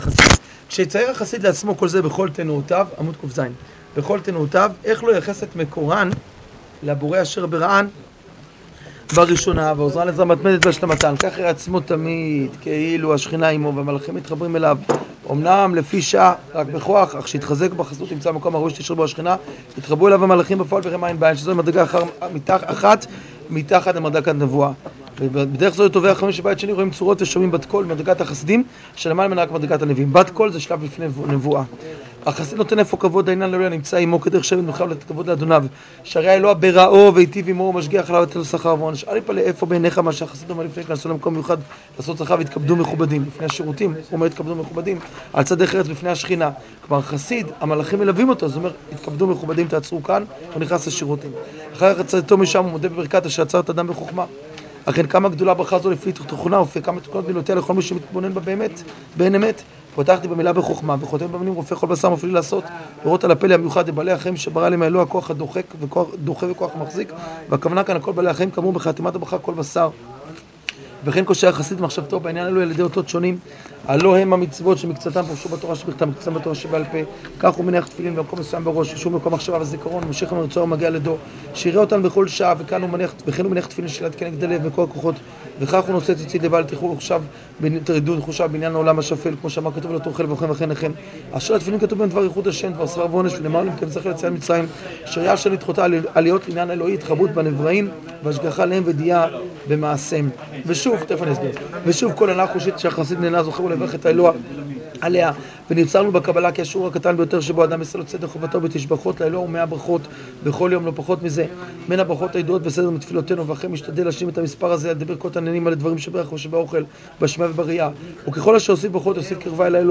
שיעור שיימר החסיד